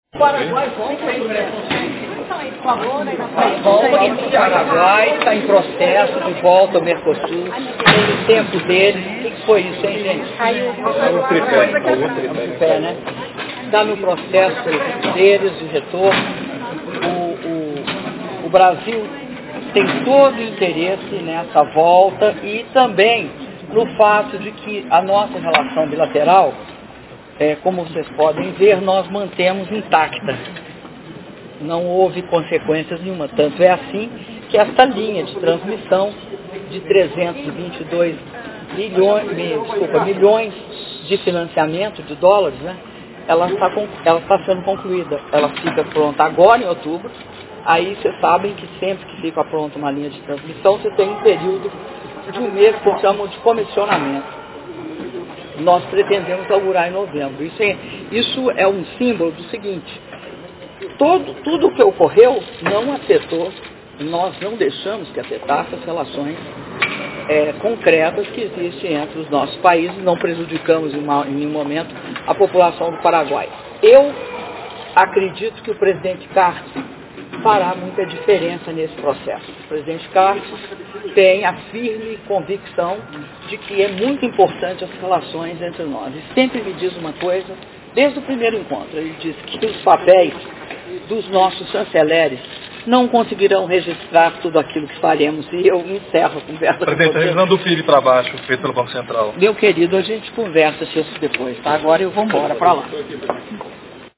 Áudio da entrevista concedida pela Presidenta da República, Dilma Rousseff, após encontro bilateral com o presidente da República do Paraguai, Horacio Cartes - Brasília/DF (1min44s)